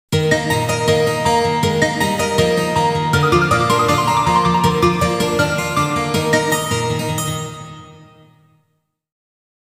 少々長い曲もありますが、発車ベルとしての機能を果たせるように心掛け製作しました。